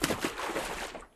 water_splash01.wav